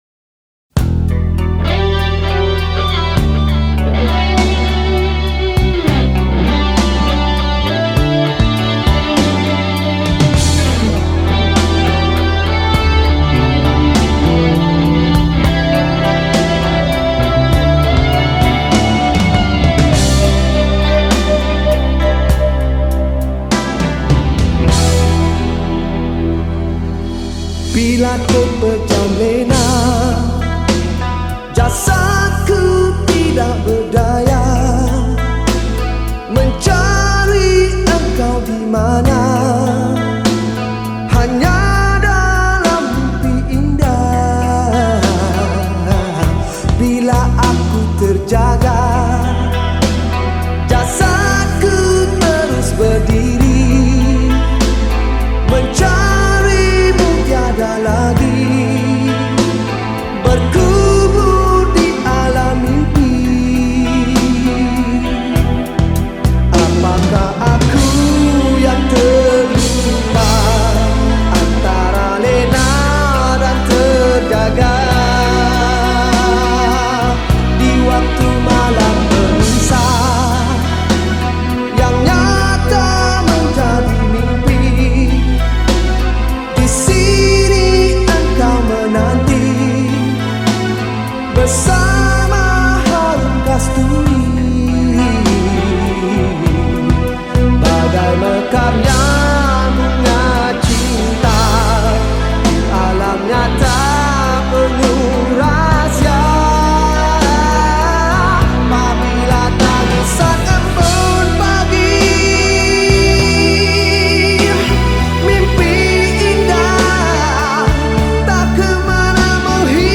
Rock Kapak
Skor Angklung